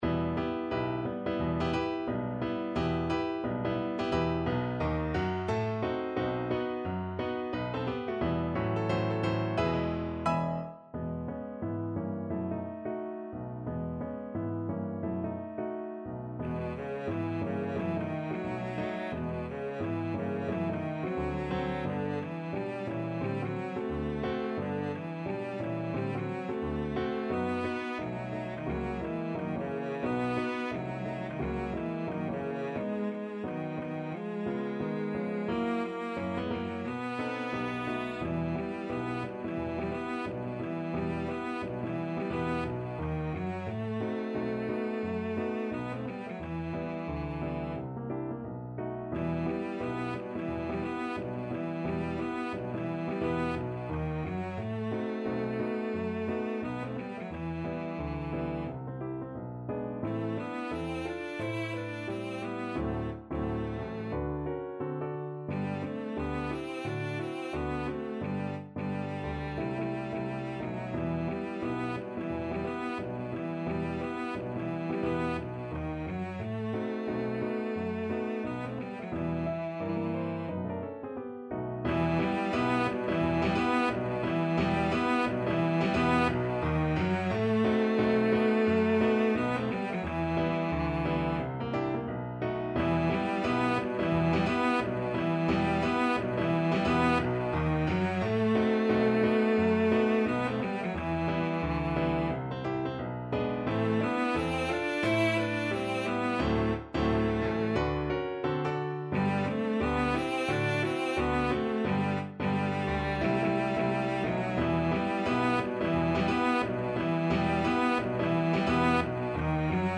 ~ = 176 Moderato
2/2 (View more 2/2 Music)
Jazz (View more Jazz Cello Music)
Rock and pop (View more Rock and pop Cello Music)